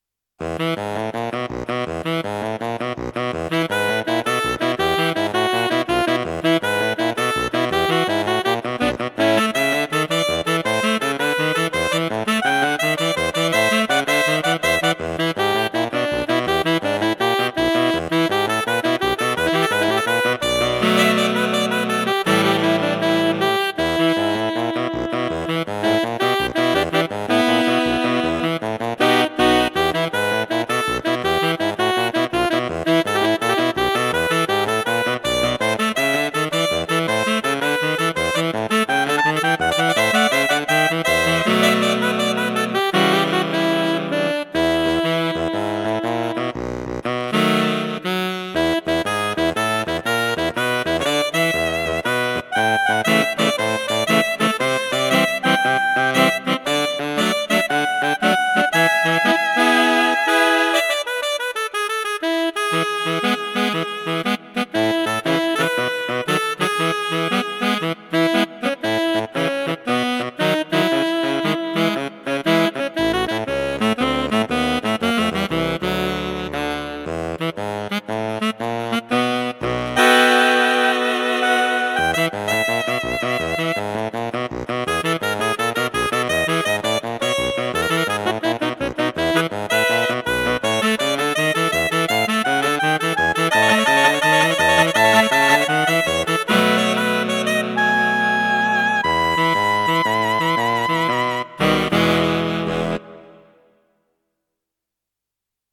WAS DER AFFE AM MONTAG GESPIELT HAT (SAXOPHON)